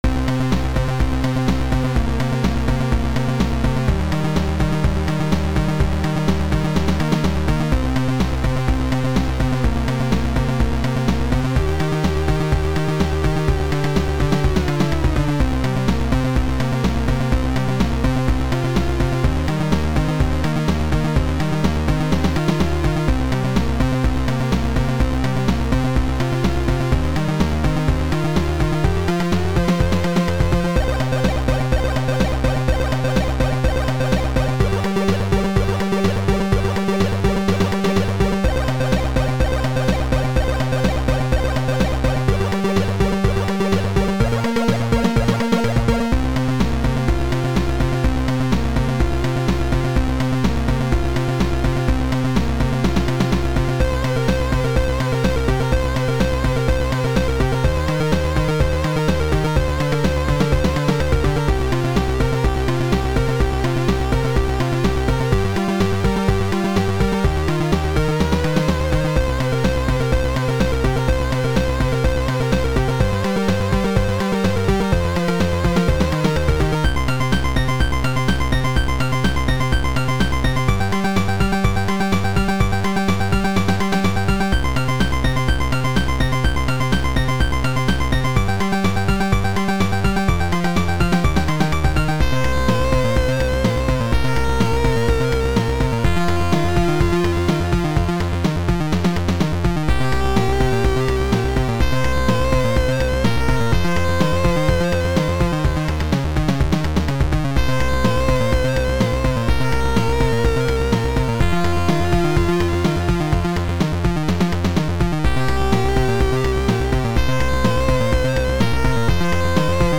Music Assembler Module